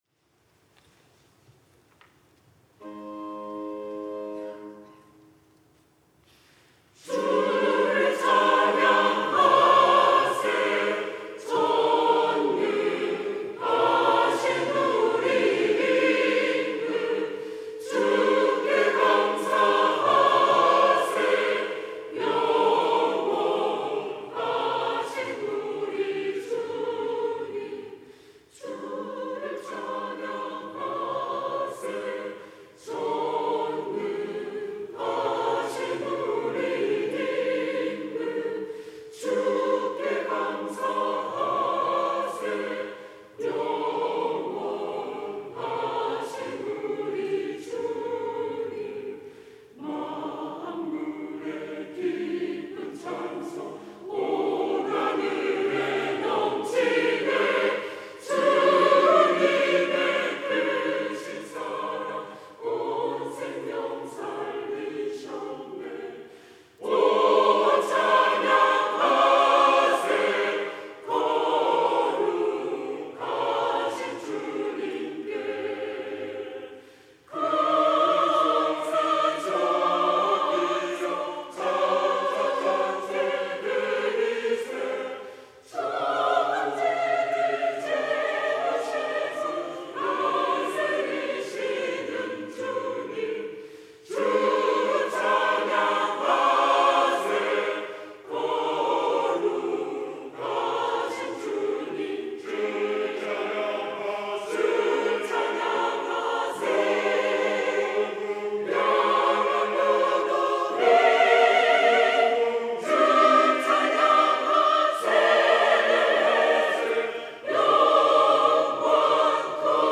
시온(주일1부) - 주를 찬양하세
찬양대